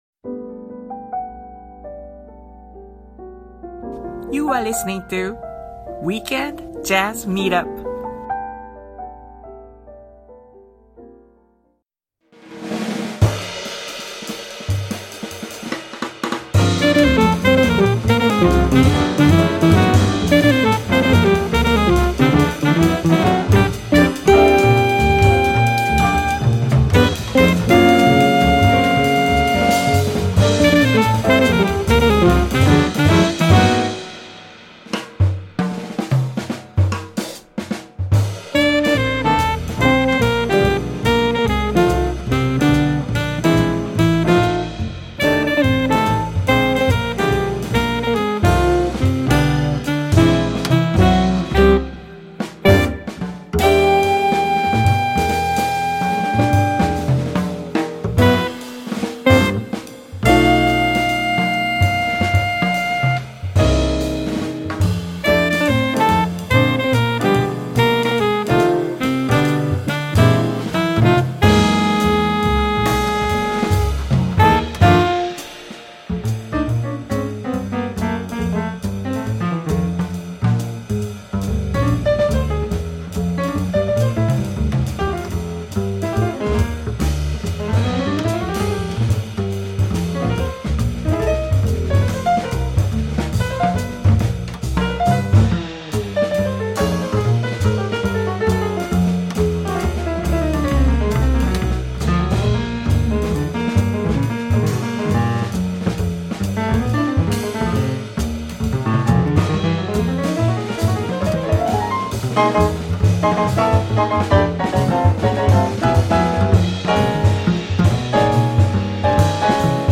piano
ライブ音源・沖縄ソロライブ